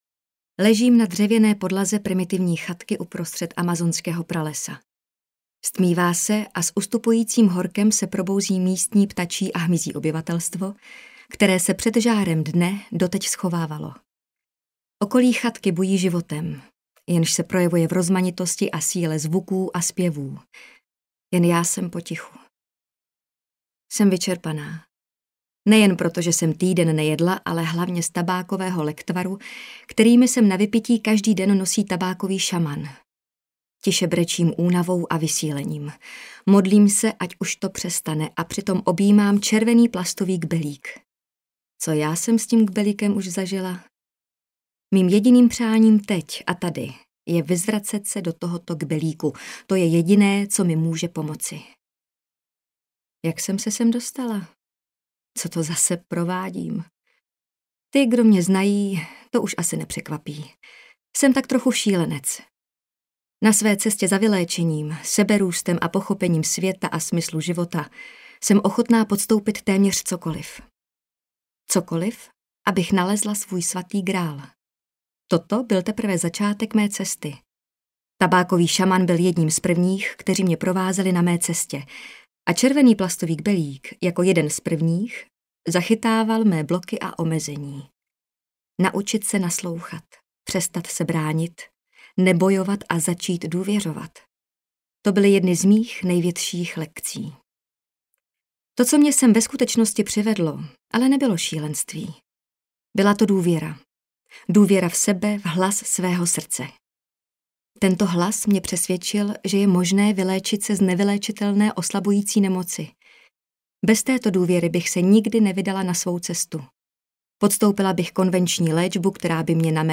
audiokniha
Čte: Jana Plodková